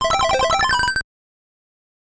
Звуковой эффект получения бонусной жизни за высокий счет